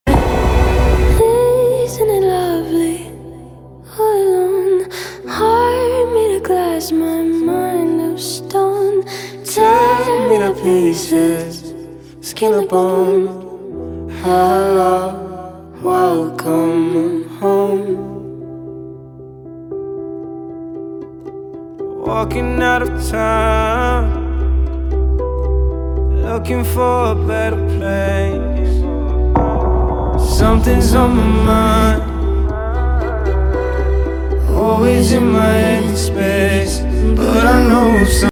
chamber pop ballad